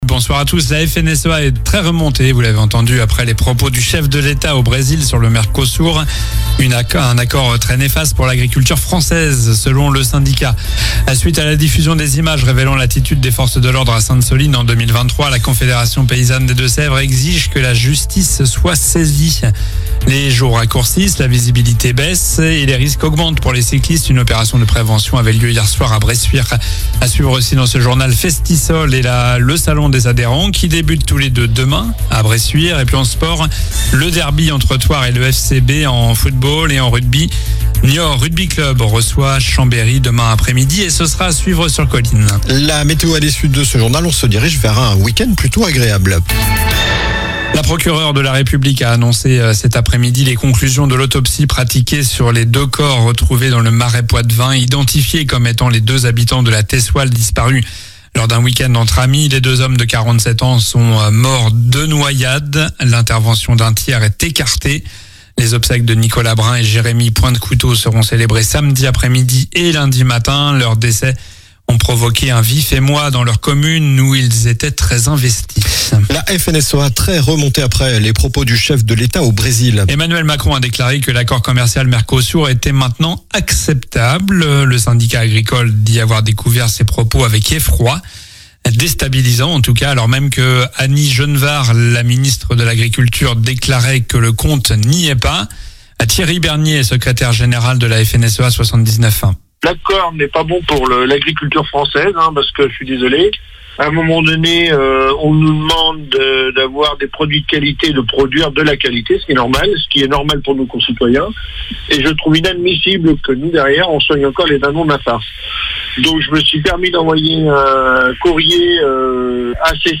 Journal du vendredi 7 novembre (soir)